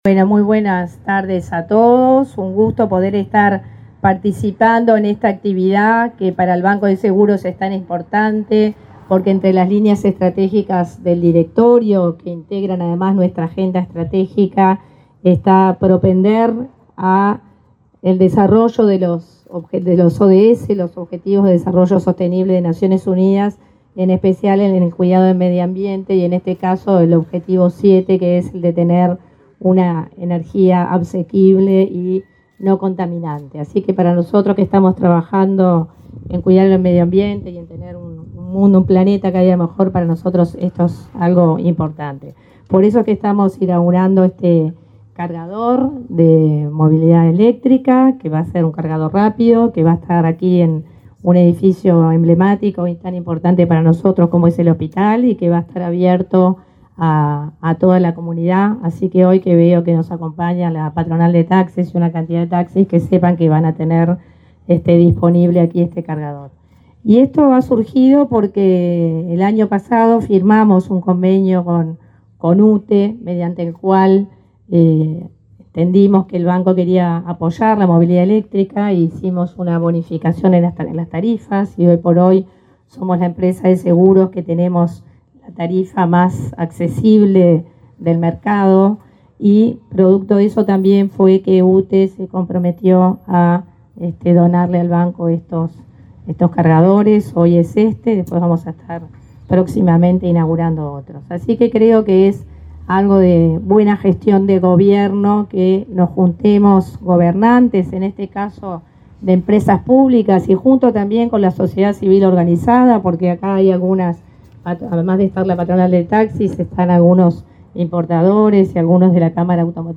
Palabras de autoridades en acto de UTE